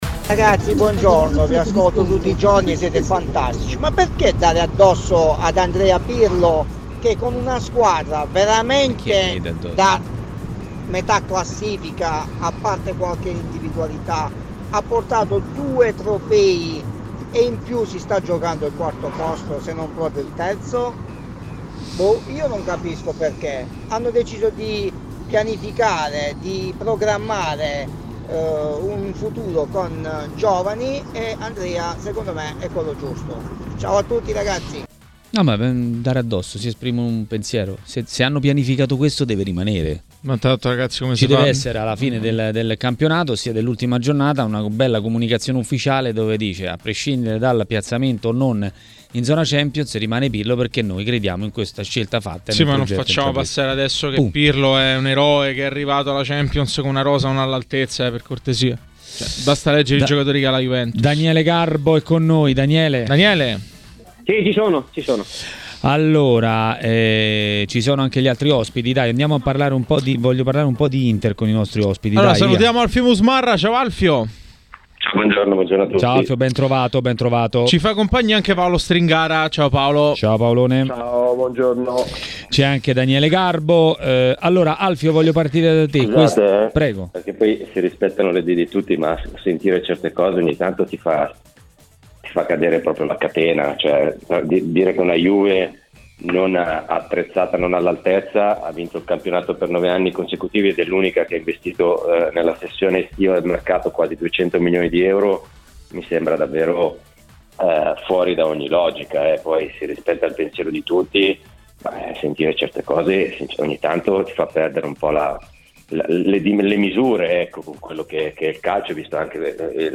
L'ex calciatore Paolo Stringara a Maracanà, nel pomeriggio di TMW Radio, ha parlato della situazione in casa Inter e non solo.